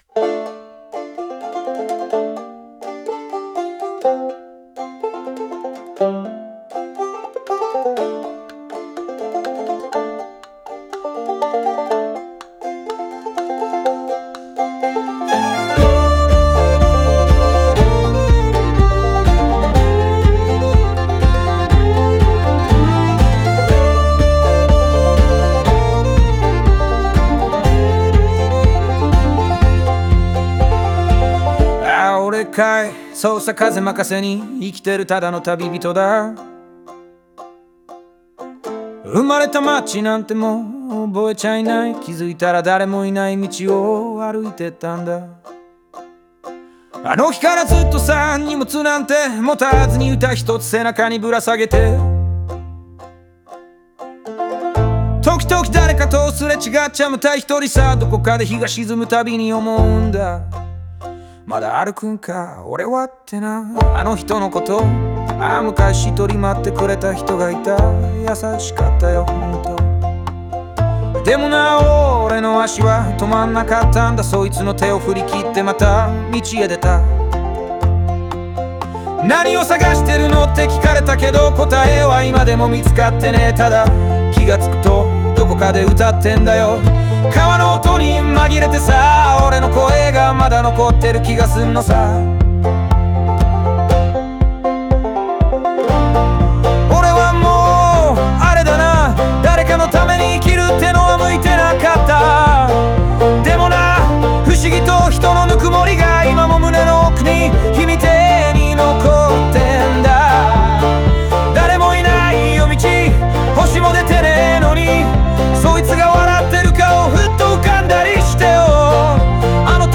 オリジナル曲♪
この歌は、故郷や過去を捨てて旅を続ける流れ者の心情を、語り口調で淡々と、しかし深く歌い上げたものです。